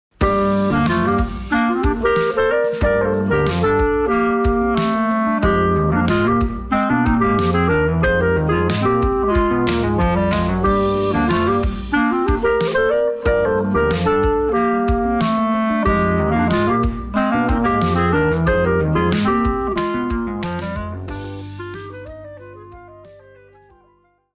Tight, syncopated articulations and a chilled vibe.